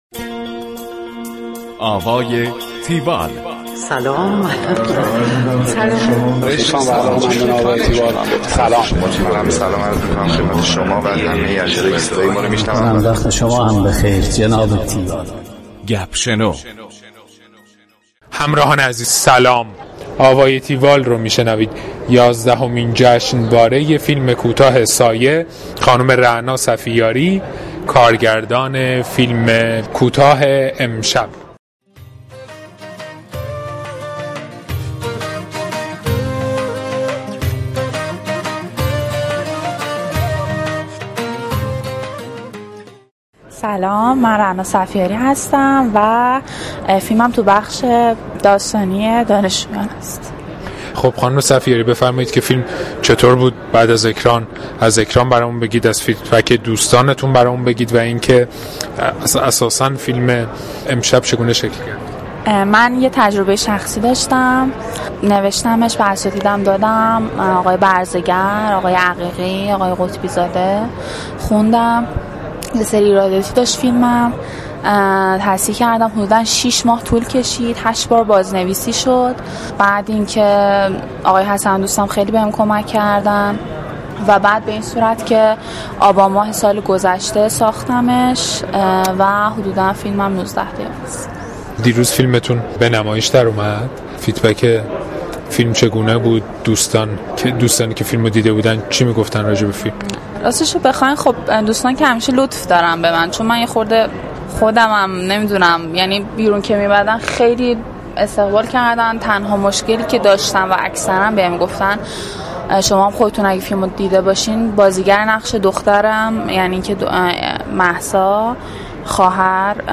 گفتگو کننده
گفتگوی تیوال